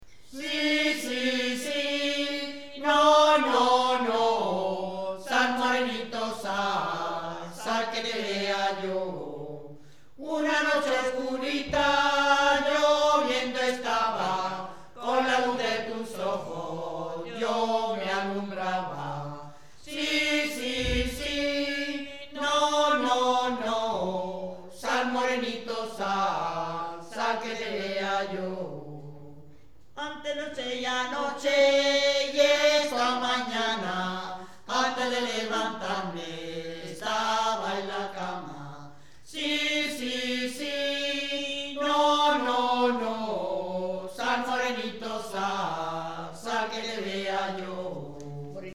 A continuación os transcribimos algunas de ellas y las acompañamos del sonido original en las gargantas de algunos de aquellos "mozos" que ya no lo son tanto...